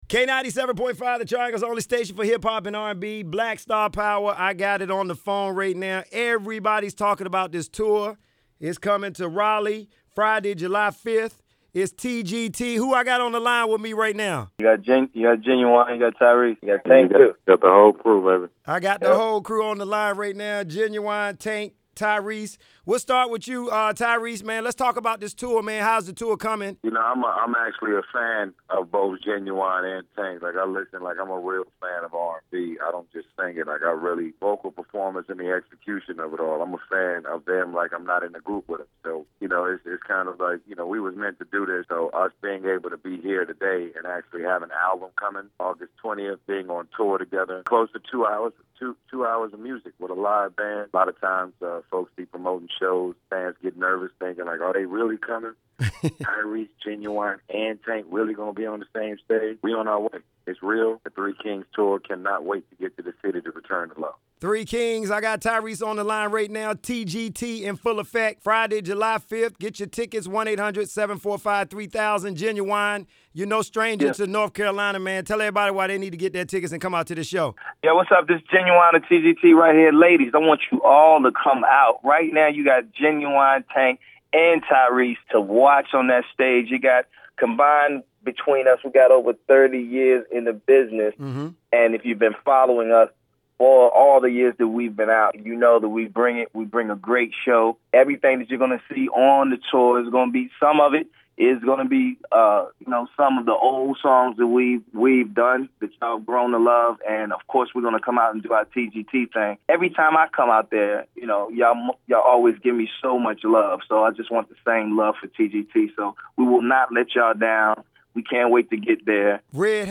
TGT Interview ✕
tgt-interview1.mp3